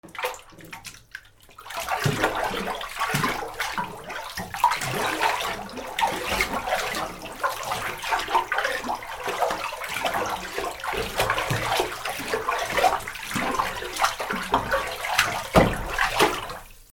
水音 水をかき回す
『バシャバシャ』